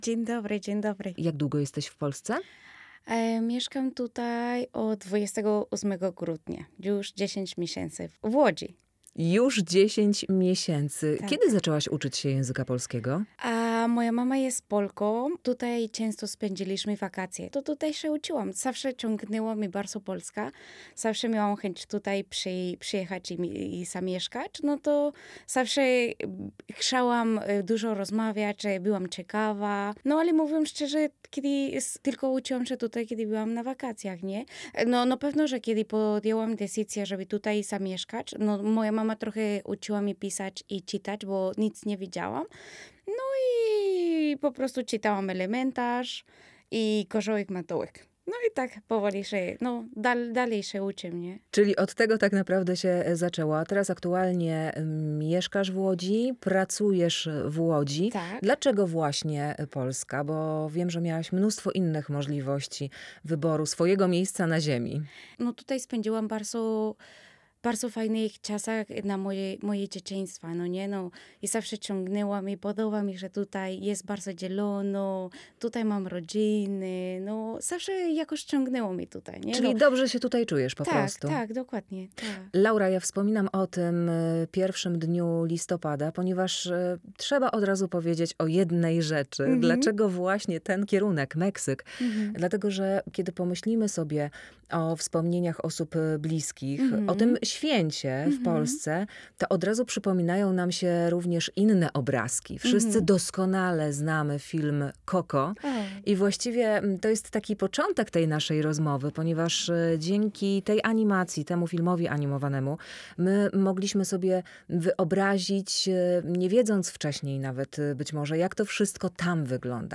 Día de Muertos w Łodzi, czyli o tradycjach meksykańskiego Święta Zmarłych [ROZMOWA] - Radio Łódź